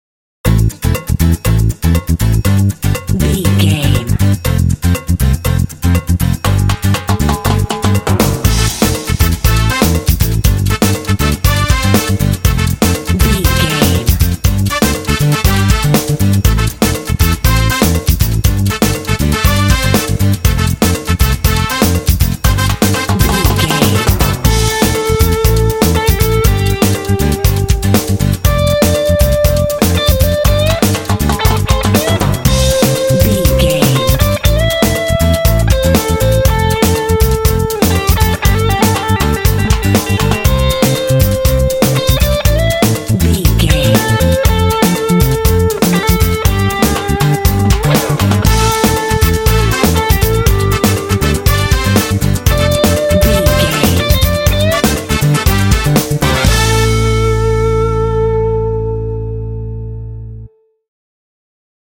This smooth and energetic track is great for racing games
Uplifting
Aeolian/Minor
smooth
lively
driving
percussion
drums
bass guitar
brass
electric guitar
latin